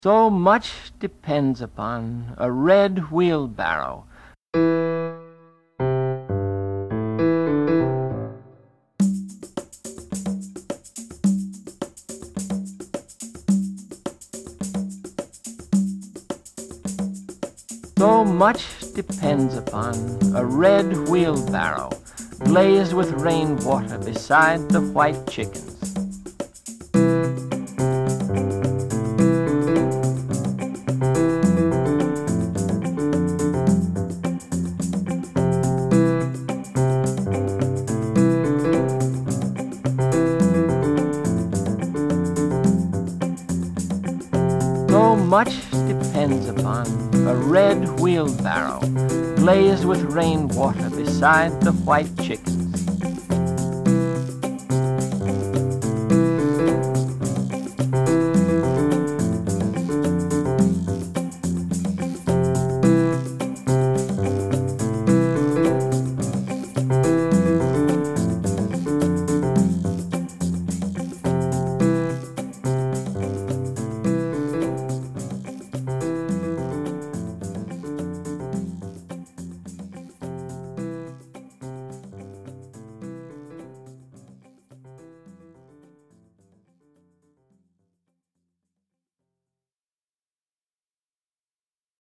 One might think “The Red Wheelbarrow” would lend itself to a more rustic musical setting (stringband, maybe?), but the piano figure drawn, DNA-style, from Williams’s voice seemed to fit with some congas, shakers and a vaguely reggae-tinged guitar rhythm.
Includes original audio of the poem: